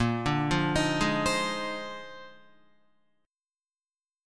A#11 chord